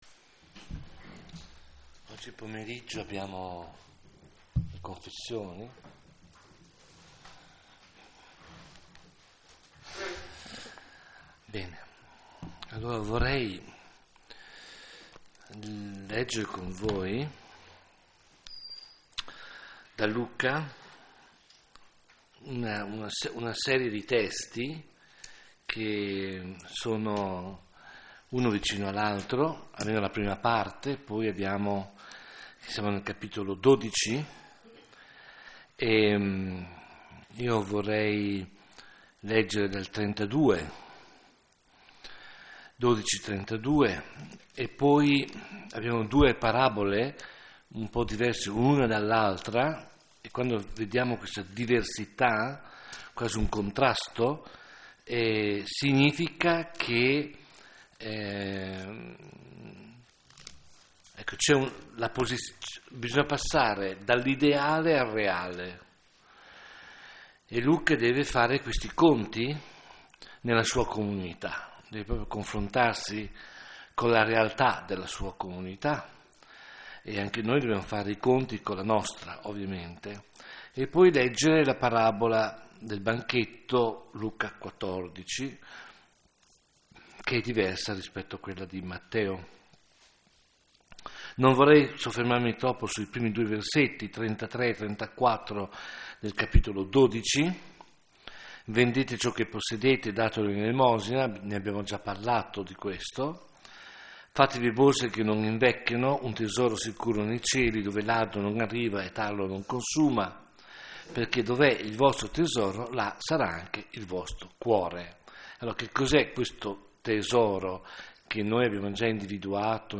Serie: Meditazione